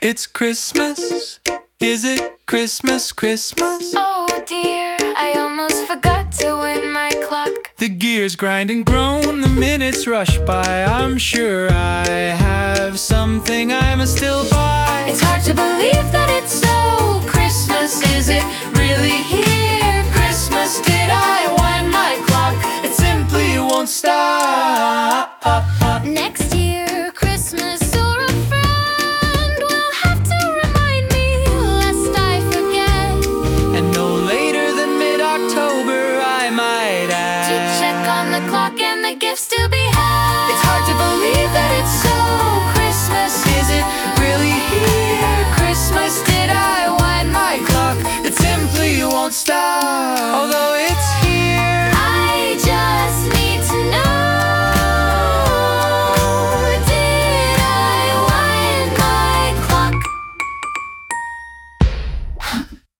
intro-fast-nervous-humming-ticking-.mp3